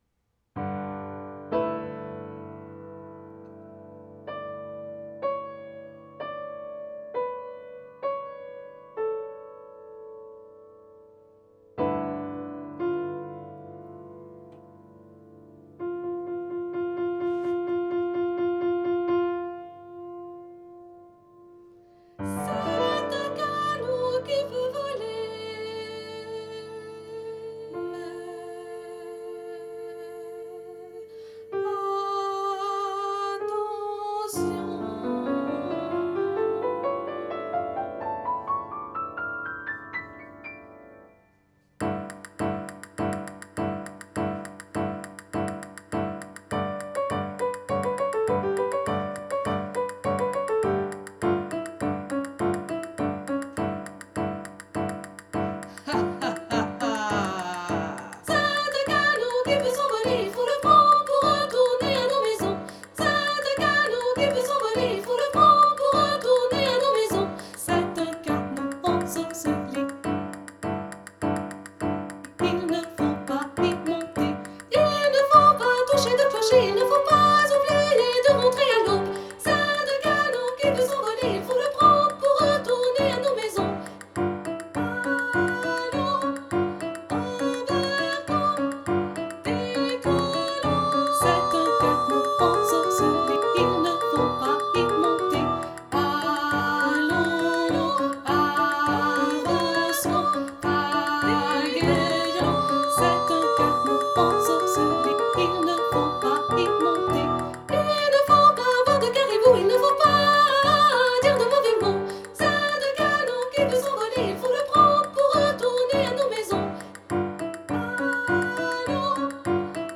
Créative et amusante, les enfants adorent cette chanson avec les petits rires et les cuillères.
Audio de la chanson voix 2